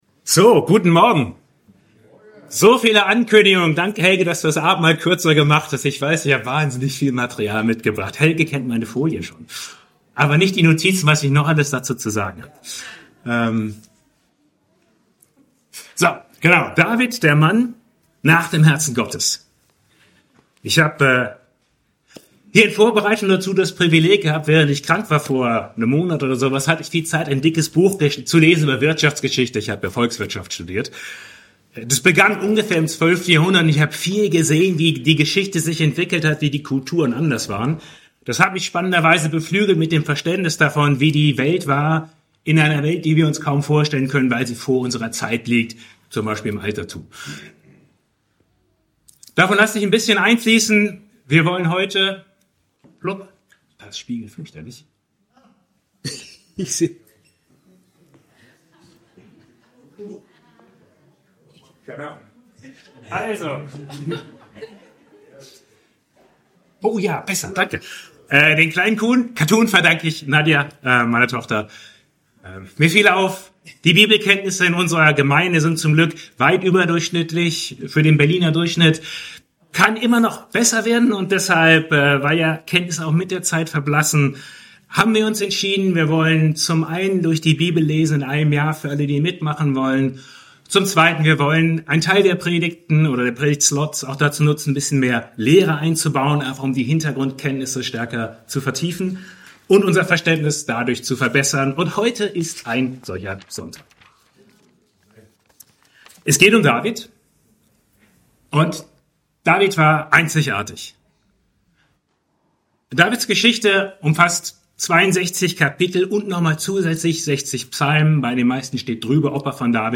Predigten GD Berliner Gemeinde Christi